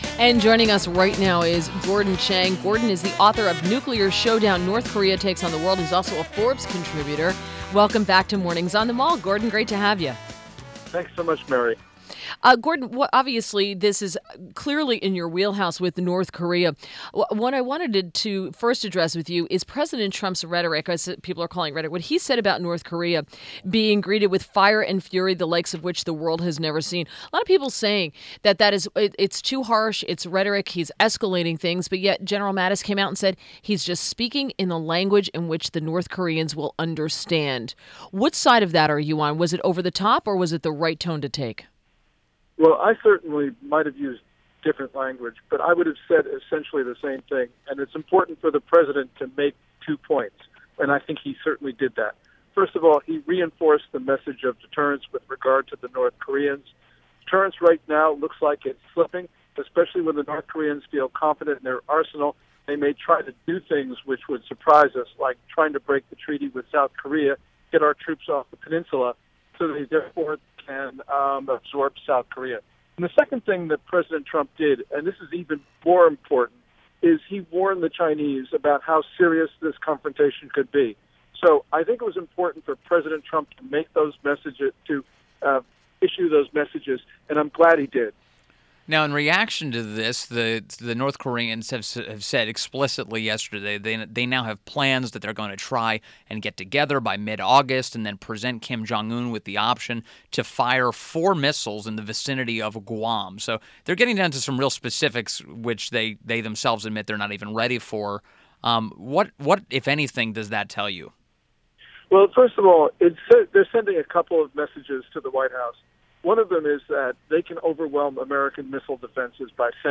WMAL Interview - GORDON CHANG - 08-10-17